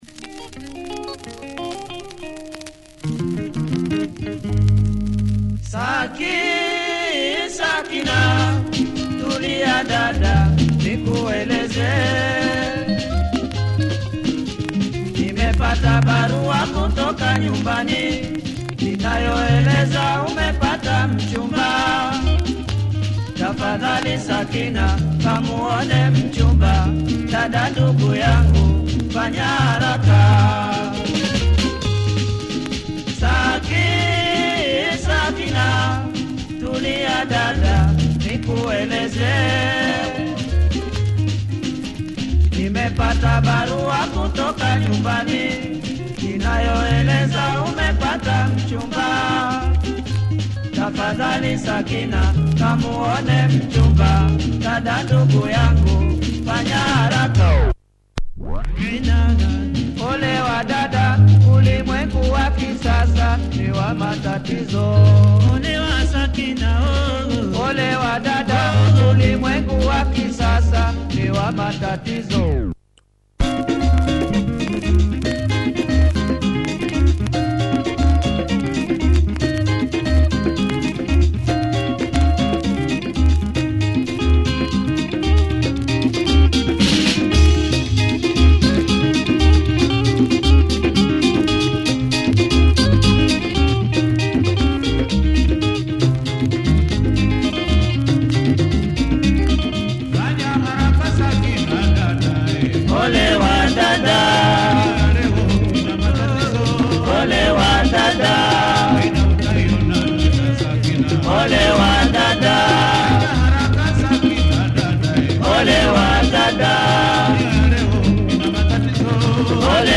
Great Tanzanian rumba! Super horns section too!
Plays with some noise in the start.